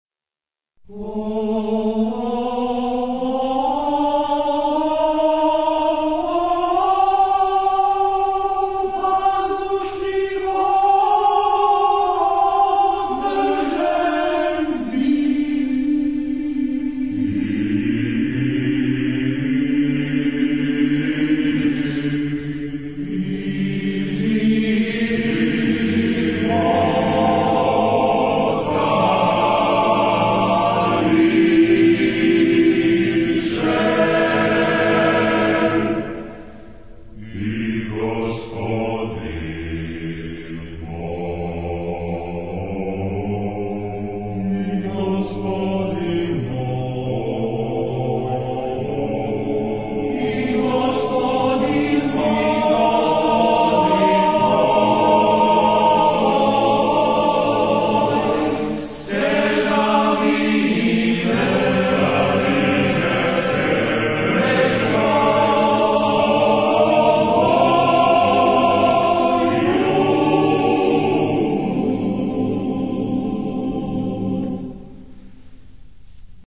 Genre-Stil-Form: weltlich ; zeitgenössisch ; Madrigal
Charakter des Stückes: optimistisch ; ernst
Chorgattung: TTBB  (4-stimmiger Männerchor )
Solisten: Tenors (2)  (2 Solist(en))
Tonart(en): polytonal